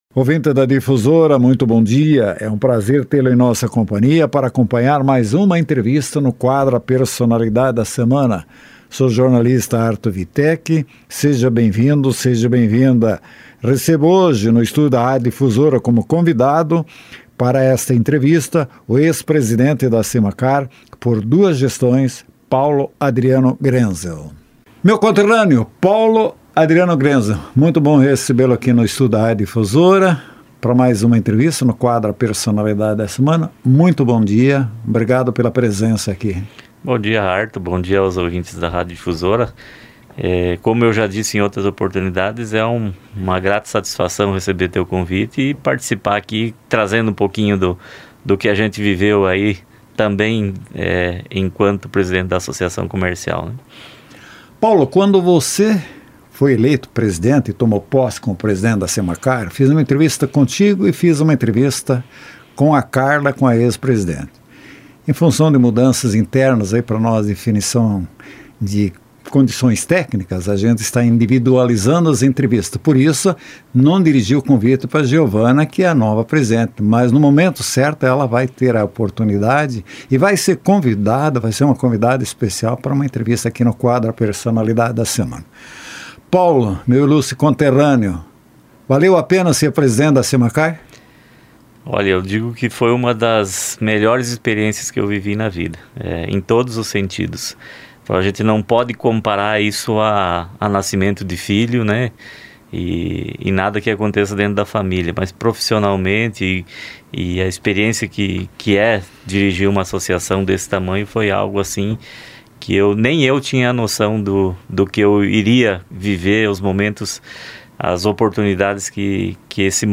A interlocução foi levada ao ar pela emissora nos dias 26 e 27 de abril de 2025.